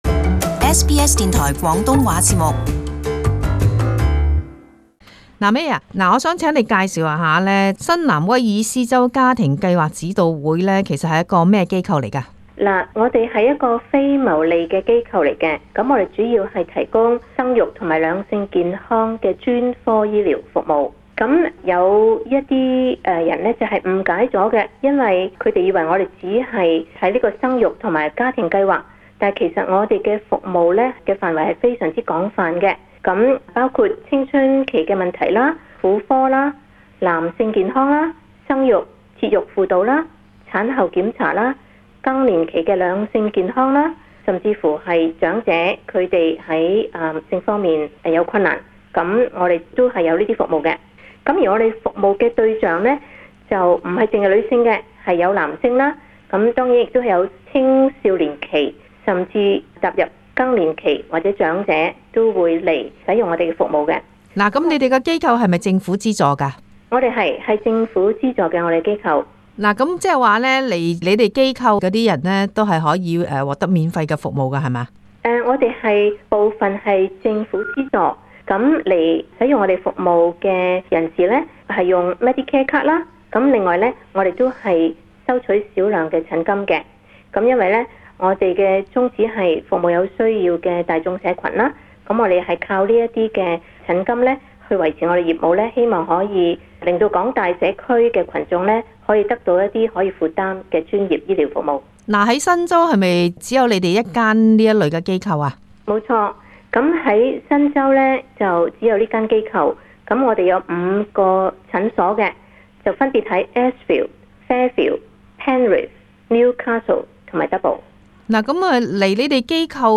【社團專訪】新南威爾士州家計會服務多樣化